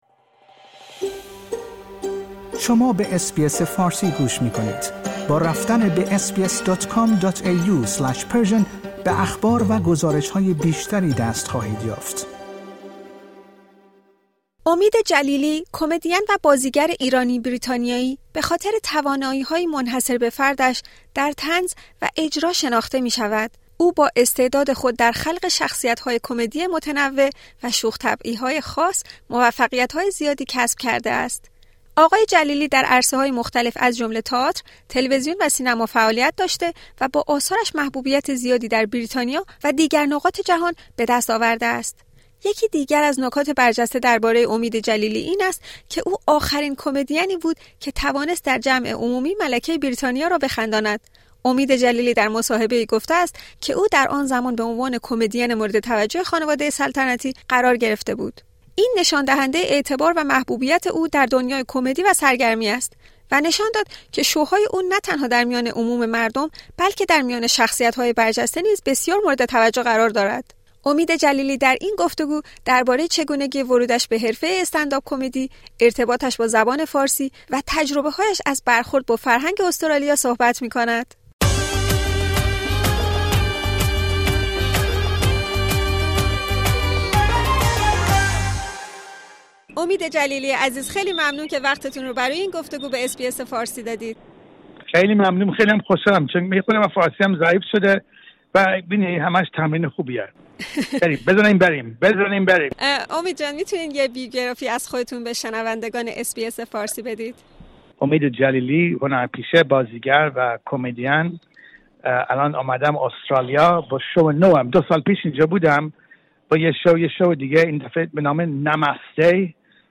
امید جلیلی، کمدین و بازیگر ایرانی-بریتانیایی که برای تور جدید خود «ناماسته» به استرالیا آمده در گفت‌وگویی با اس‌بی‌اس فارسی از چگونگی ورودش به حرفه استندآپ کمدی، ارتباطش با سرزمین مادری و برخوردش با فرهنگ استرالیا صحبت می‌کند.